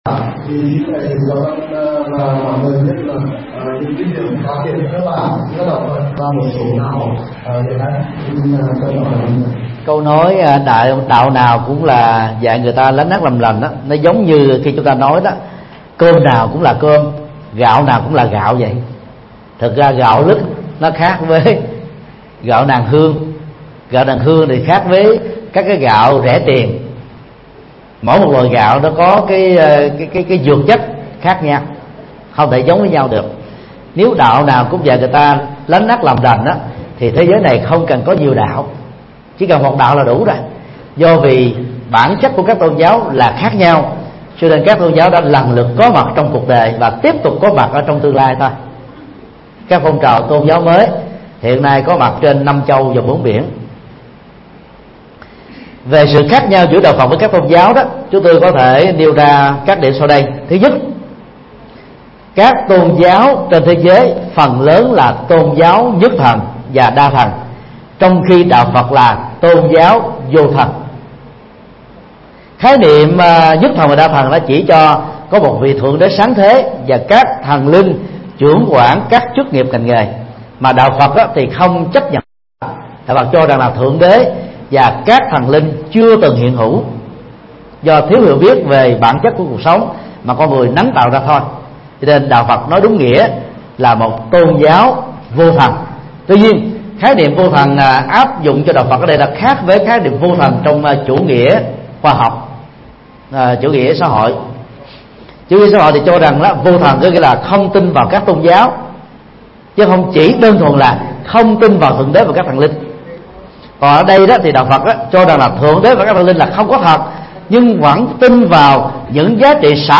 Vấn đáp: Sự khác nhau giữa đạo Phật và các tôn giáo khác